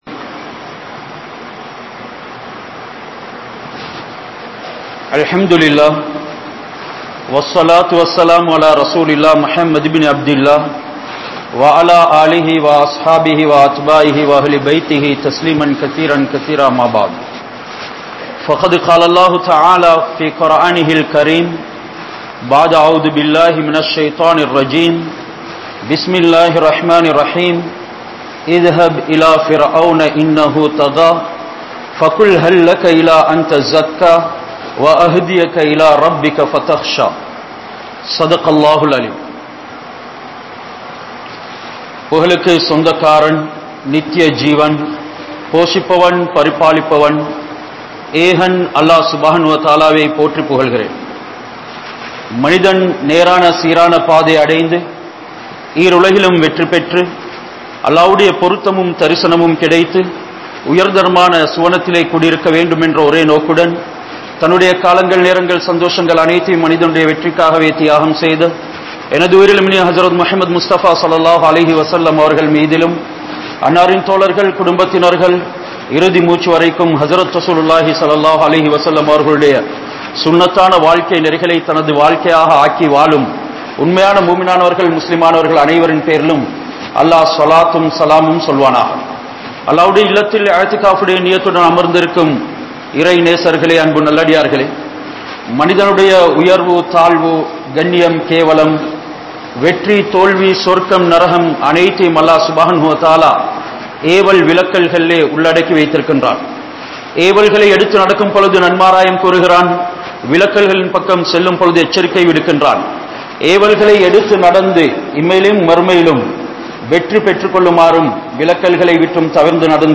Manithan Santhikka Veandiya 07 Vidaiyam (மனிதன் சந்திக்க வேண்டிய 07 விடயம்) | Audio Bayans | All Ceylon Muslim Youth Community | Addalaichenai
Kattukela Jumua Masjith